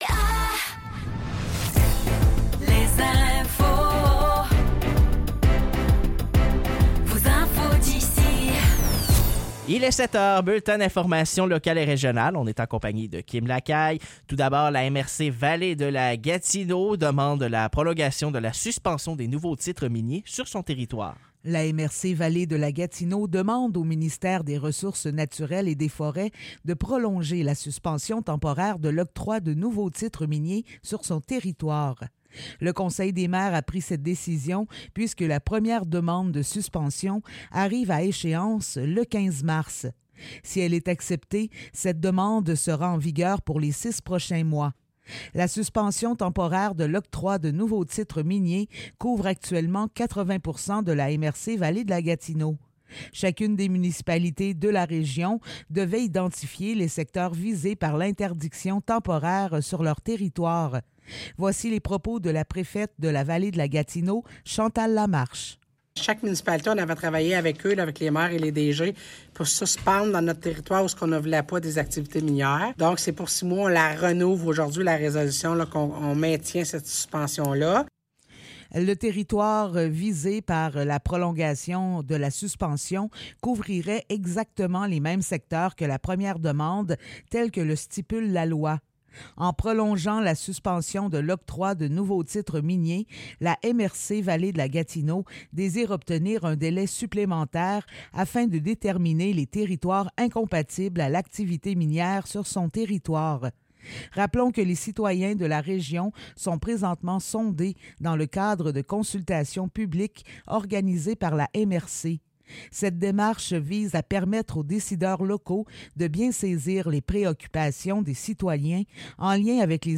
Nouvelles locales - 5 mars 2024 - 7 h